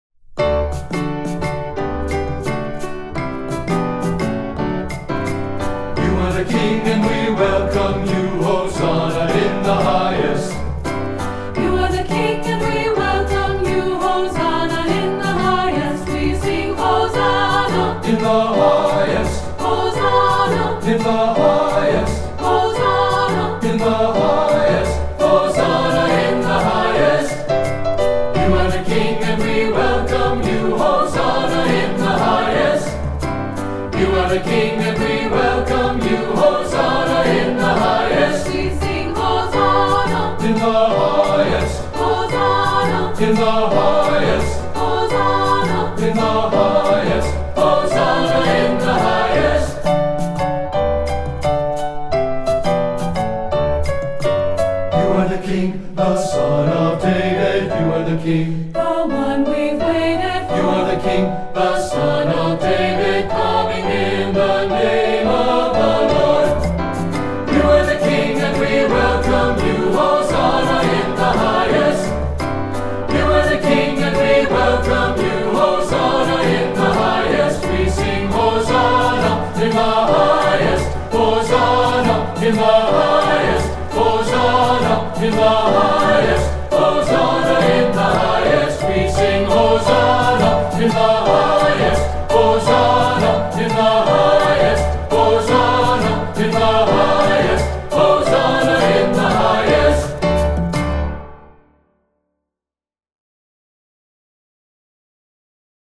Voicing: 2-part or SATB